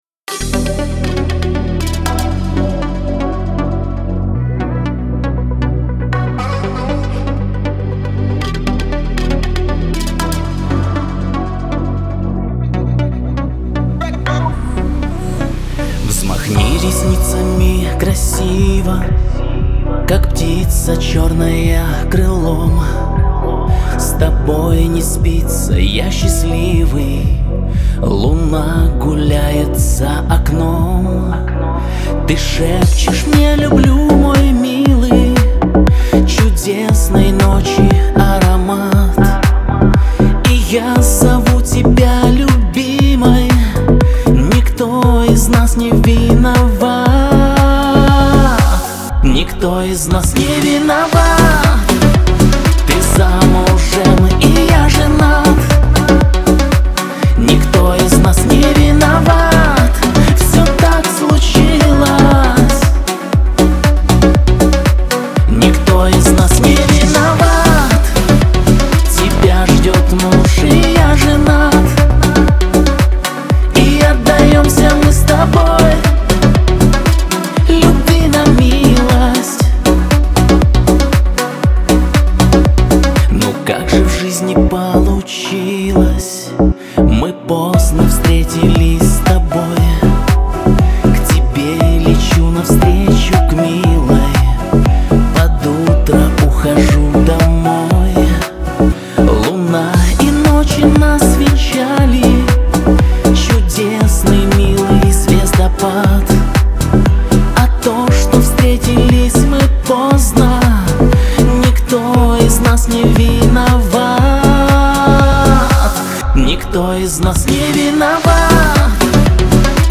диско
pop
эстрада